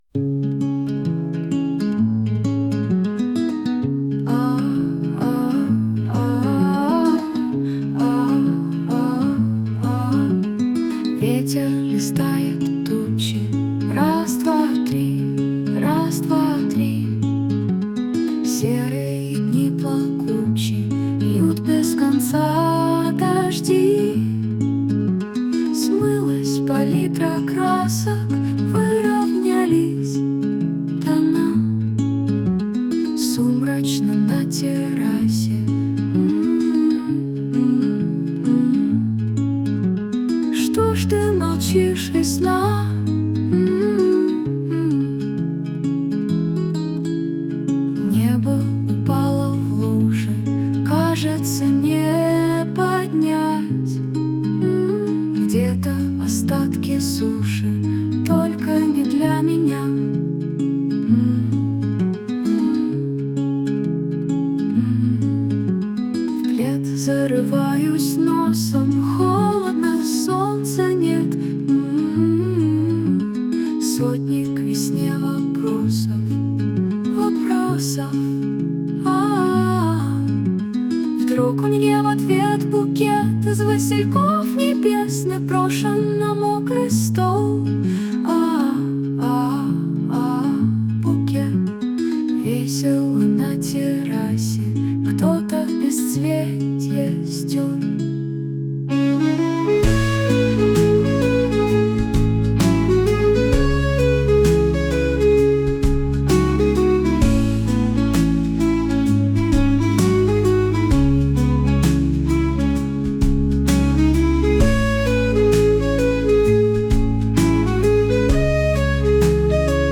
mp3,4474k] Авторская песня